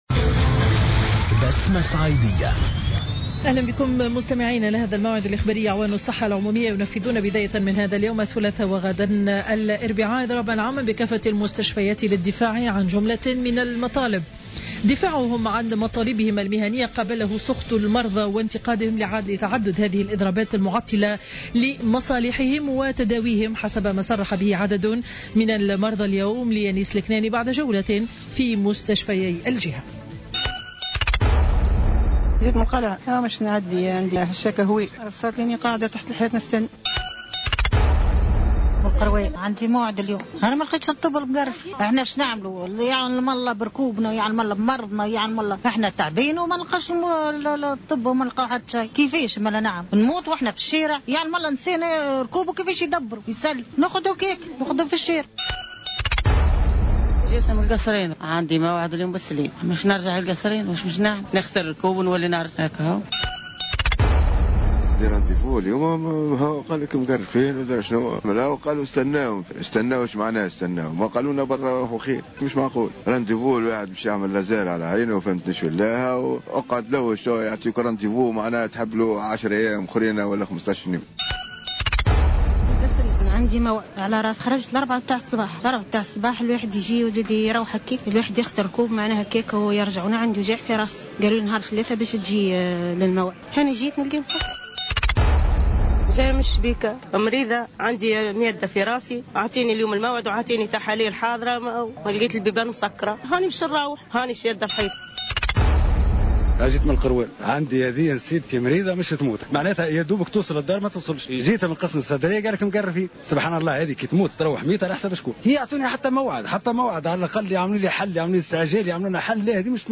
نشرة أخبار منتصف النهار ليوم الثلاثاء 28 أفريل 2015